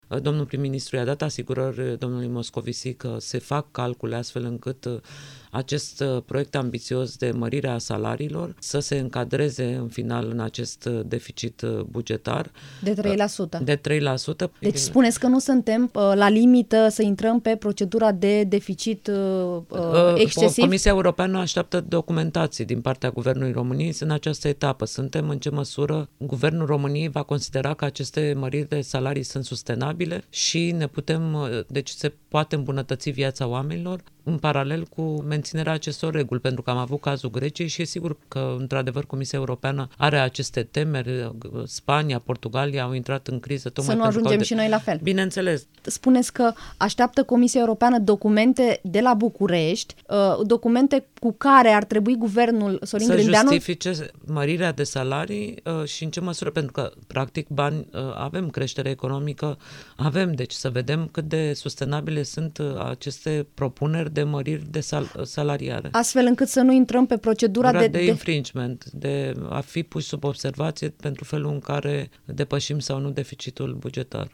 Invitată la Interviurile Europa FM, Corina Crețu, comisarul european pentru Politici Regionale, a declarat că la Bruxelles există temerea ca România să nu ajungă precum Grecia.
20mar-18-Corina-Cretu-INTERVIU-Comisia-asteapta-dovezi-sa-nu-ne-bage-pe-deficit-bugetar-excesiv-.mp3